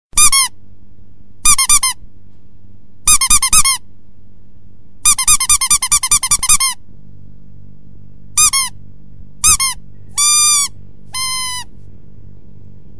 rubber-duckie_24796.mp3